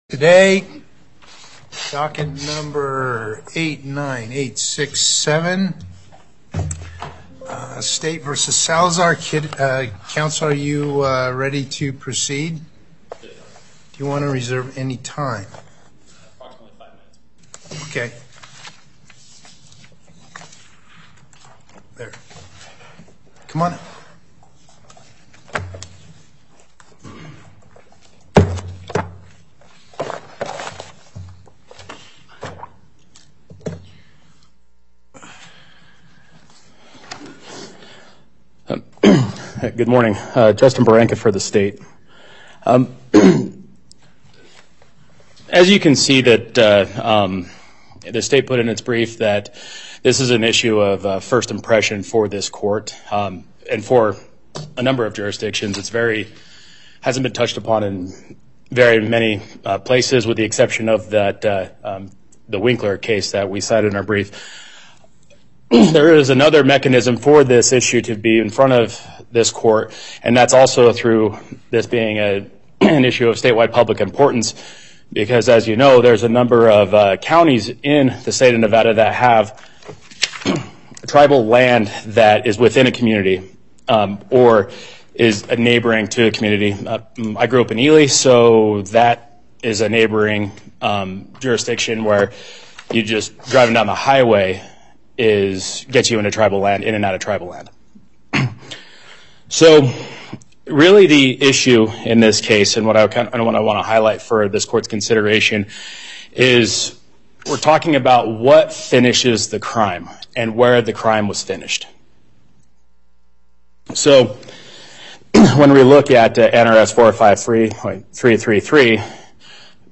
Before Panel A25 Justice Parraguirre presiding Appearances